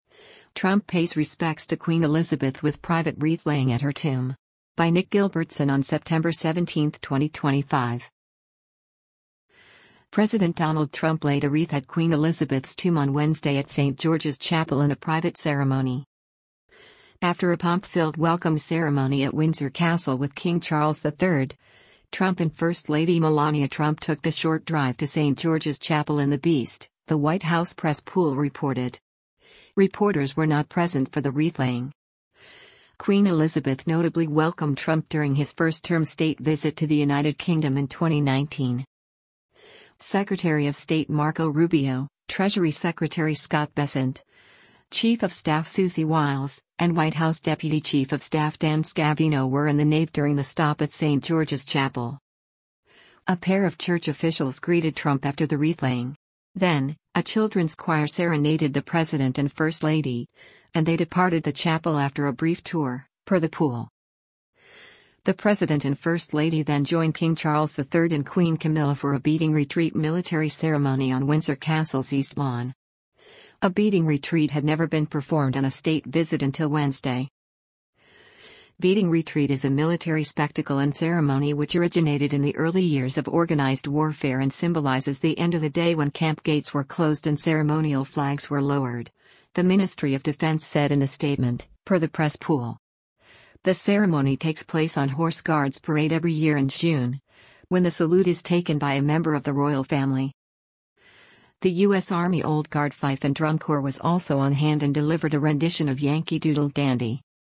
President Donald Trump laid a wreath at Queen Elizabeth’s tomb on Wednesday at St. George’s Chapel in a private ceremony.
Then, a children’s choir serenaded the president and first lady, and they departed the chapel after a brief tour, per the pool.